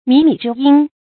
注音：ㄇㄧˇ ㄇㄧˇ ㄓㄧ ㄧㄣ
靡靡之音的讀法